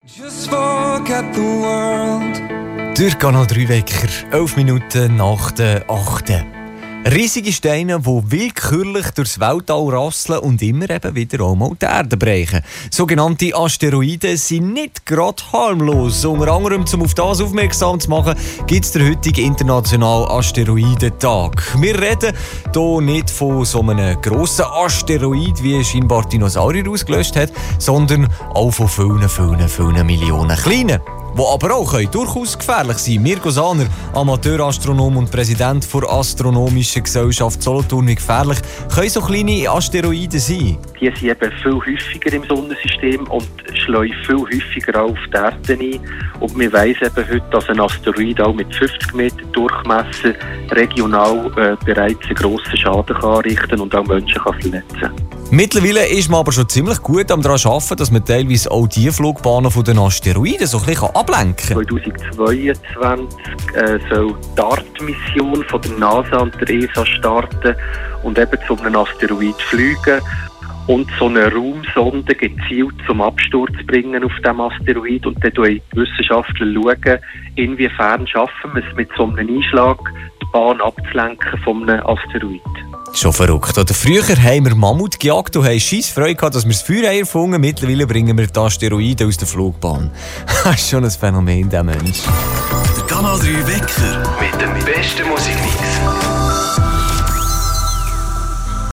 Interview zum Asteriodentag Ende Juni 2020 Canal 3 Teil #3